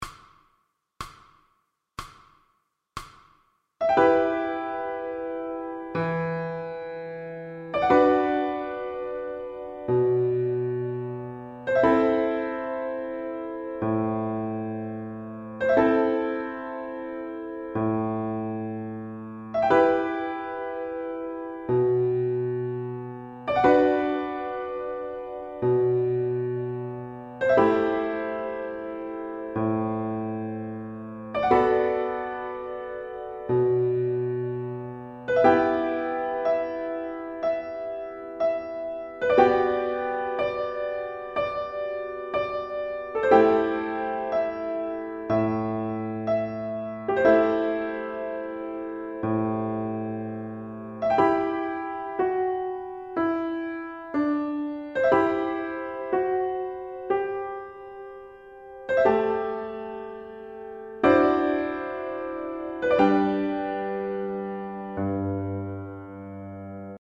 recorded piano accompaniments
Practise Speed